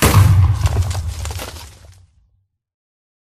explode4